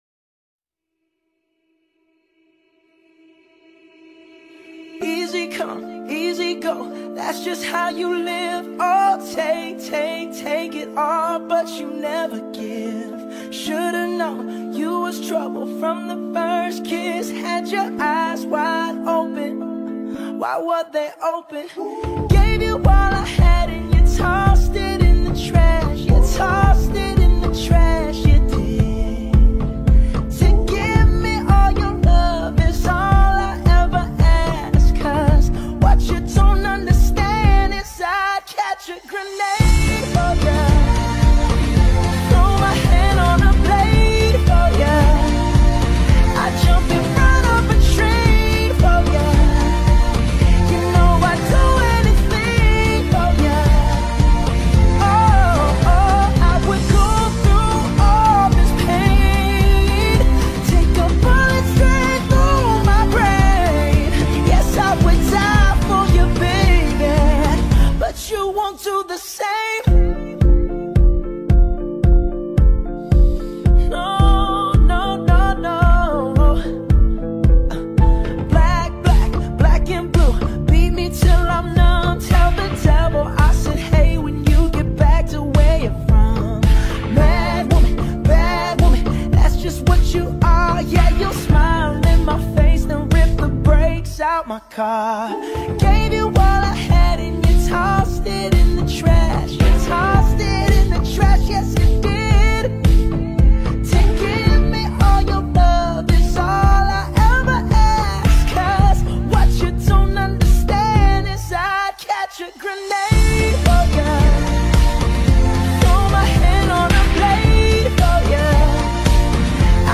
Pop, R&B, Soul